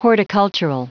Prononciation du mot horticultural en anglais (fichier audio)
Prononciation du mot : horticultural